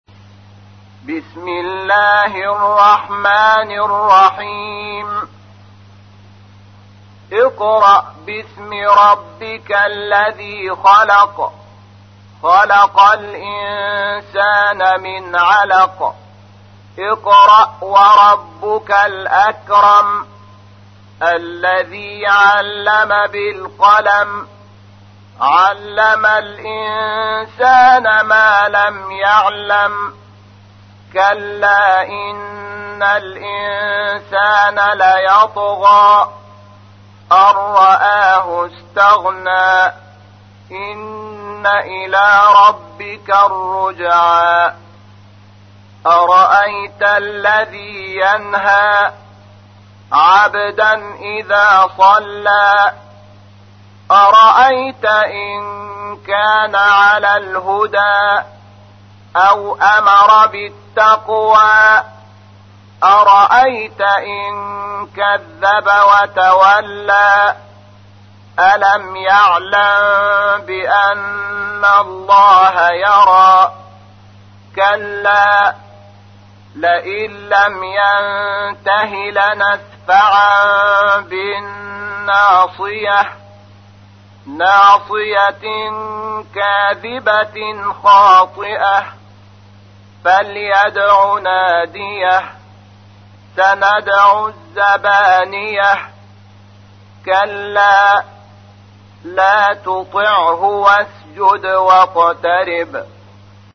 تحميل : 96. سورة العلق / القارئ شحات محمد انور / القرآن الكريم / موقع يا حسين